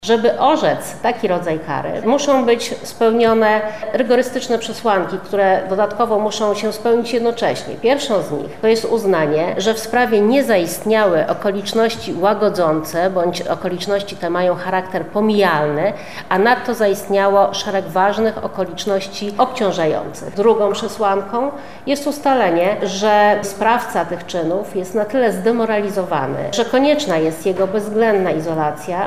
Dorota Janicka– uzasadniła brak dożywocia Dorota Janicka, sędzia Sądu Apelacyjnego w Lublinie.